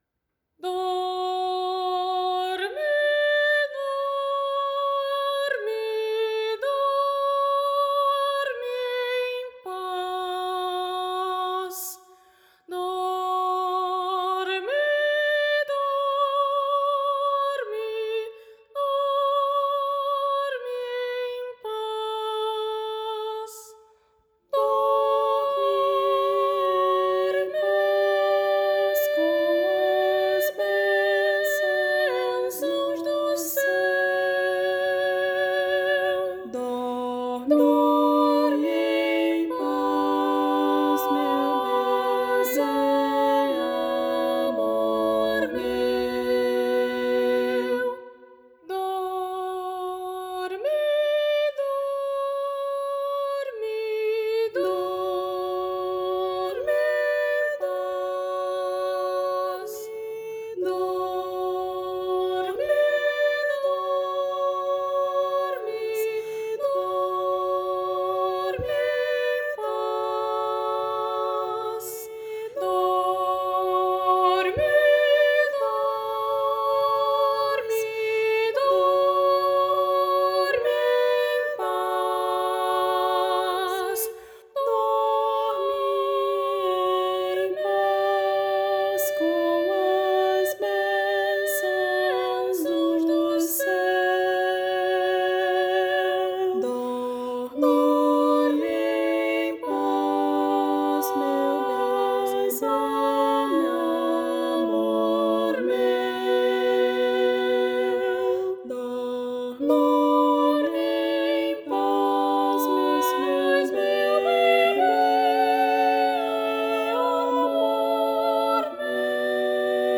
para coro infantil a três vozes
uma singela canção de ninar